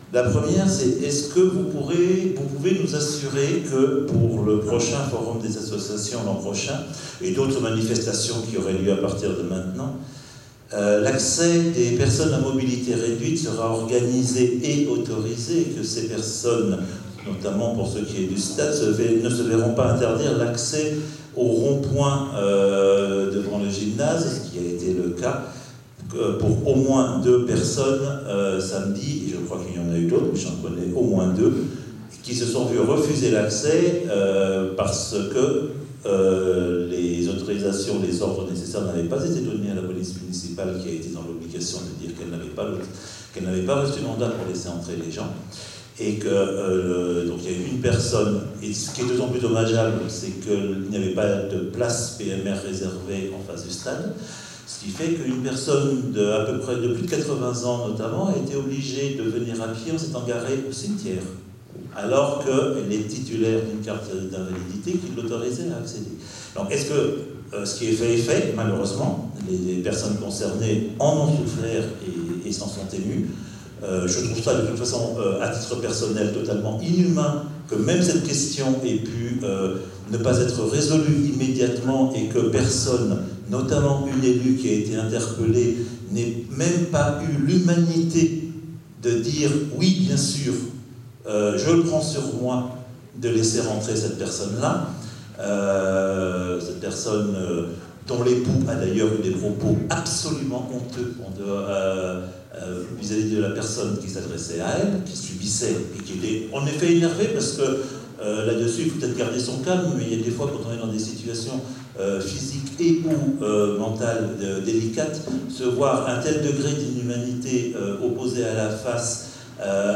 Place maintenant à l'audio de l'intervention du conseiller municipal d'un groupe de la minorité lors du conseil municipal du 14 septembre 2023: